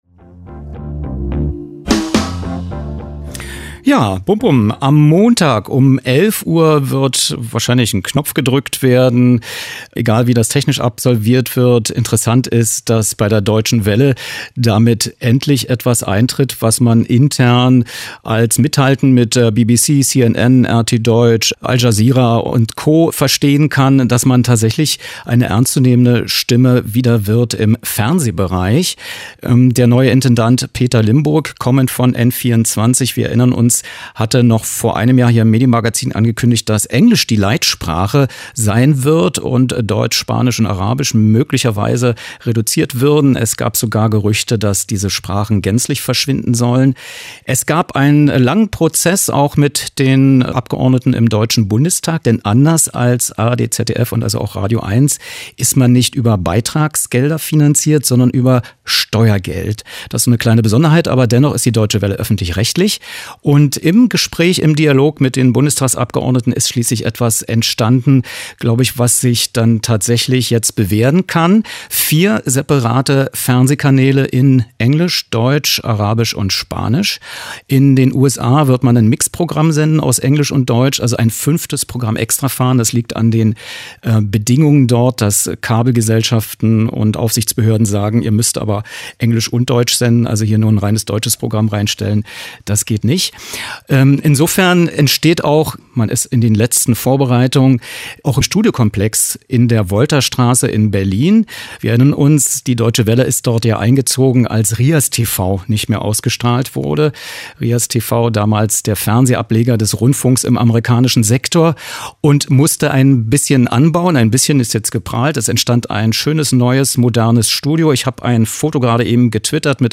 Was: Interviews im DW Sendestudio des englischen Programms
Was: Statement zur Programmreform Wer: Tabea Rößner, Sprecherin für digitale Infrastruktur BTF Bündnis 90/Die Grünen Wann: rec.: 19.06.2015, veröffentlicht im rbb Inforadio, 21.06.2015, 10:44, 15:24 Uhr